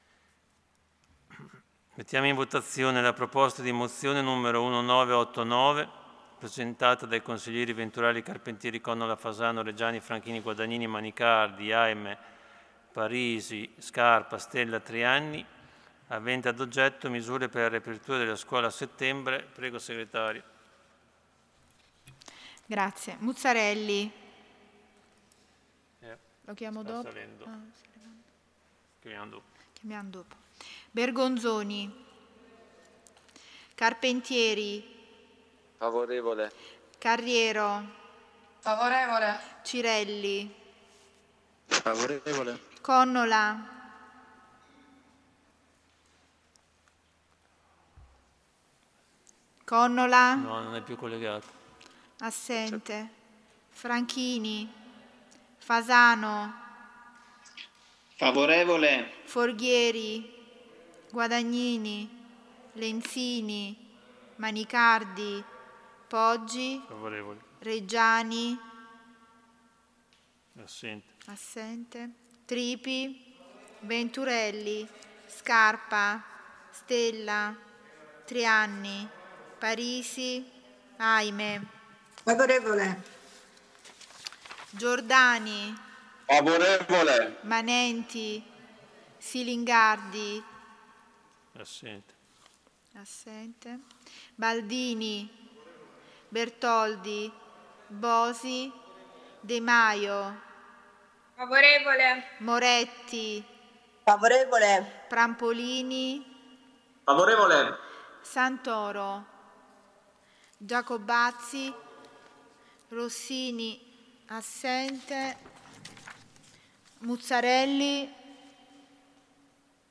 Seduta del 23/07/2020 Mette ai voti Proposta Numero 2020/1989 ORDINE DEL GIORNO AVENTE AD OGGETTO: MISURE PER LA RIAPERTURA DELLE SCUOLE A SETTEMBRE: approvata.
Audio Consiglio Comunale